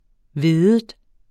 Udtale [ ˈveðəd ]